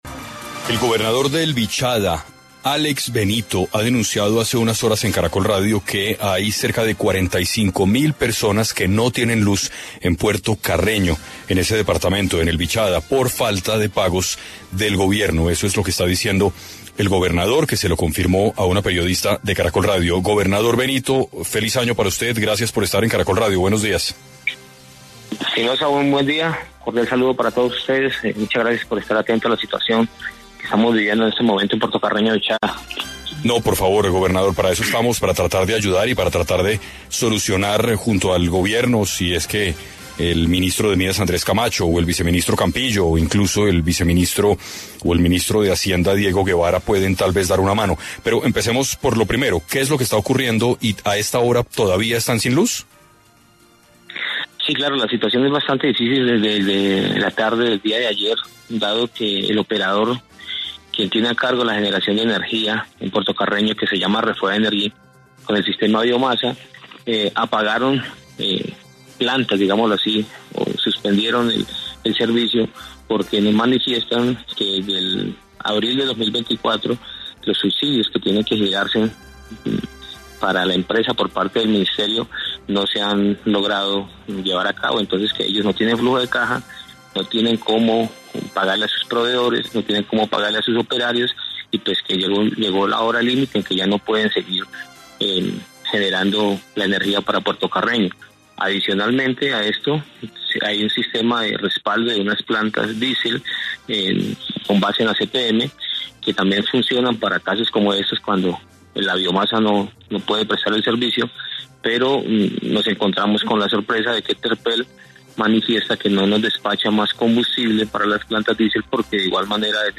En 6AM estuvo Álex Benito, gobernador de Vichada, donde explicó las razones que llevaron al municipio a quedarse sin luz.